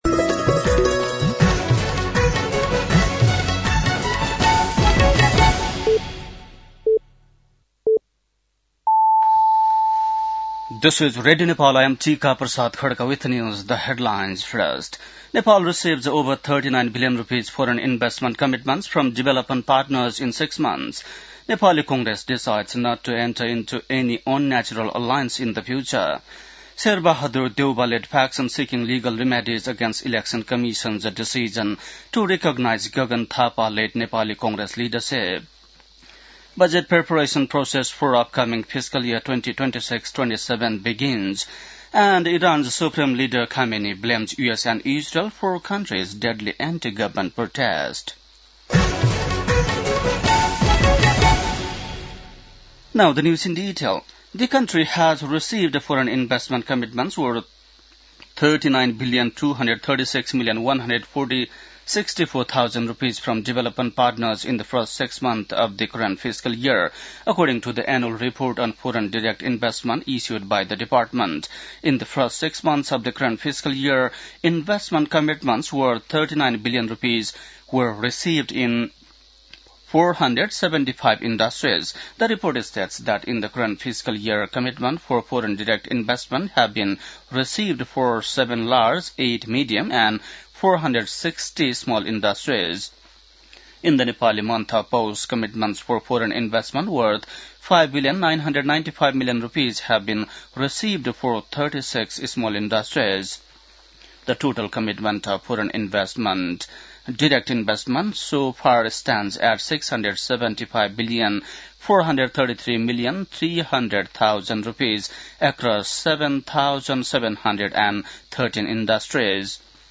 बेलुकी ८ बजेको अङ्ग्रेजी समाचार : ३ माघ , २०८२
8.-pm-english-news-.mp3